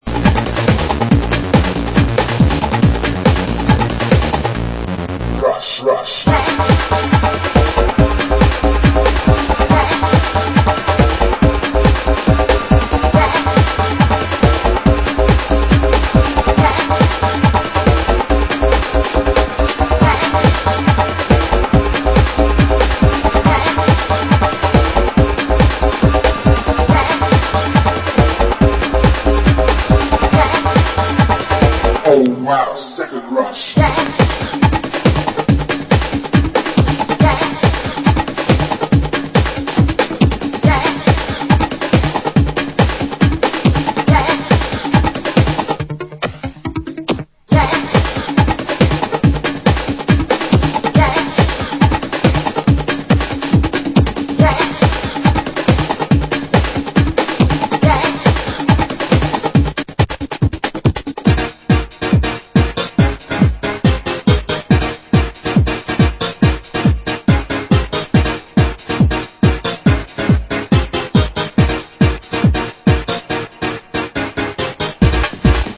Format: Vinyl 12 Inch
Genre: Hardcore